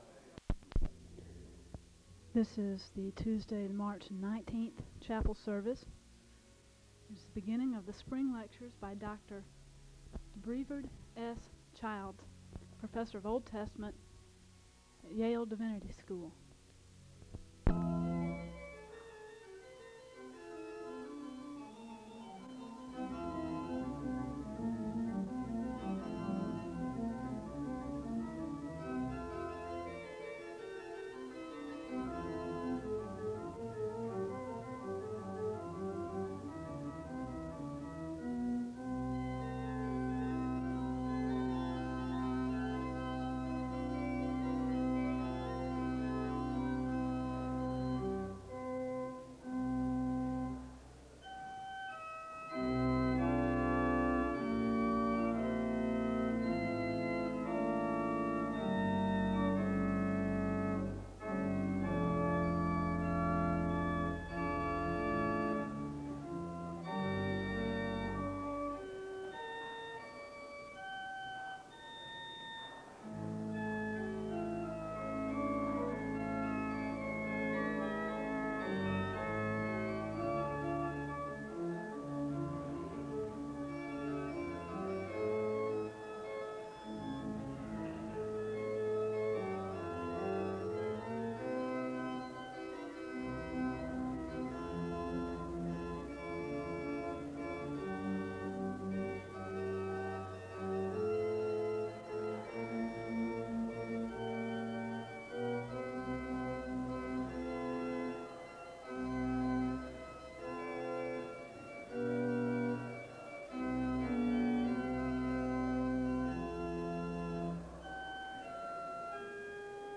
The service begins with organ music (00:00-06:18).
The service ends with organ music (57:28-58:53).